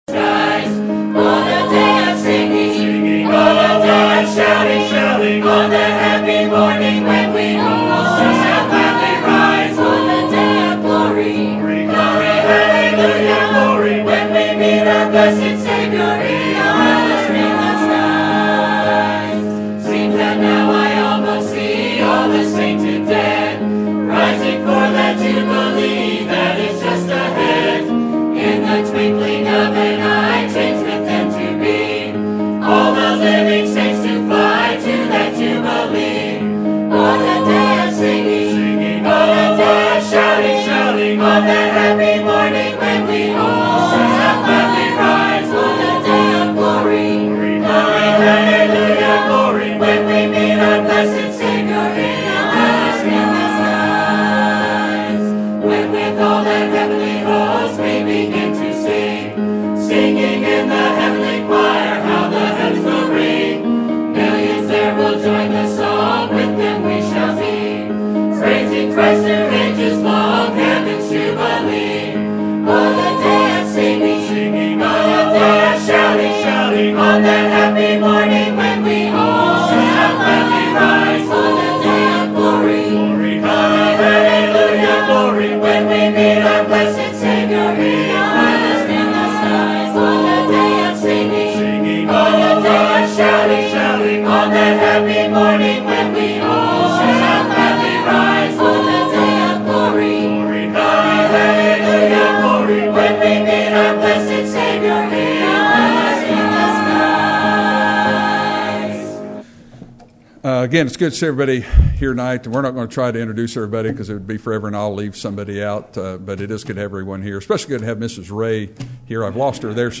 Sermon Topic: 50th Anniversary Sermon Type: Special Sermon Audio: Sermon download: Download (18.89 MB) Sermon Tags: Romans Anniversary Forgive Conformed